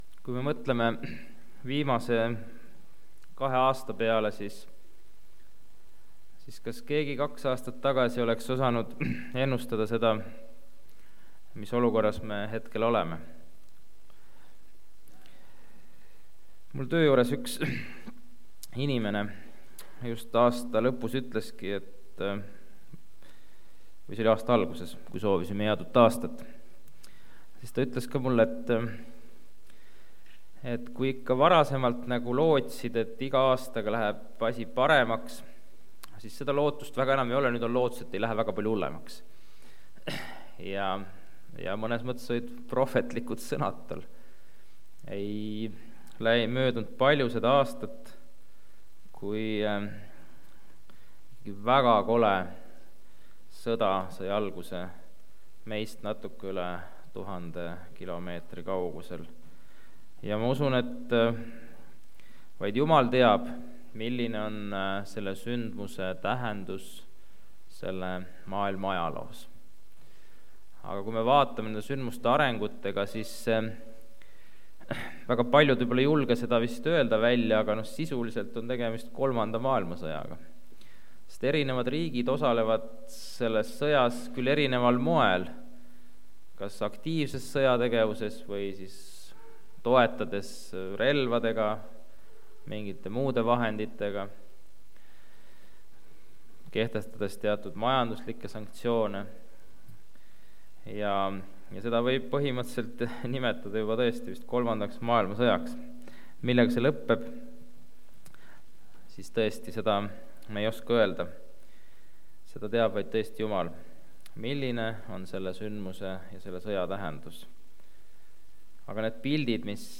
Jutlused